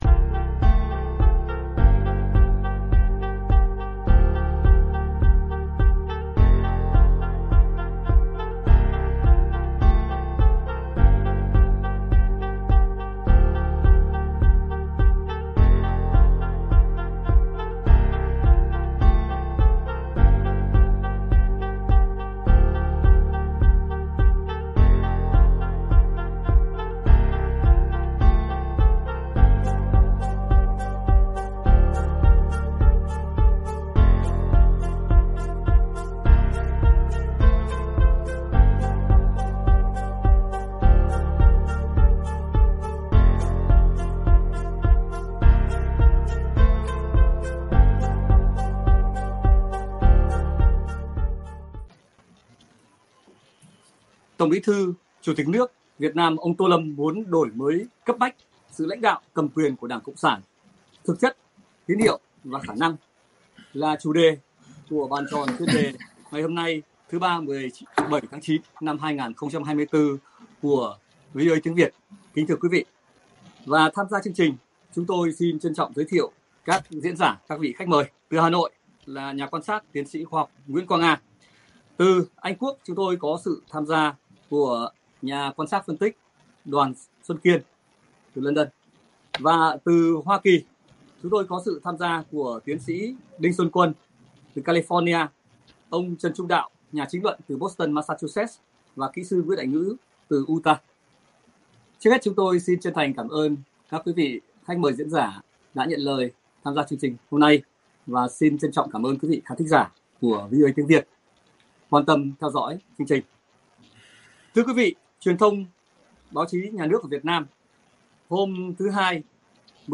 Các nhà quan sát, phân tích thời sự, chính trị từ Việt Nam và hải ngoại bình luận sự kiện truyền thông Việt Nam đồng loạt công bố bài viết của tân TBT của ĐCSVN ông Tô Lâm cho rằng đổi mới phương thức lãnh đạo và cầm quyền của ĐCSVN là yêu cầu cấp bách.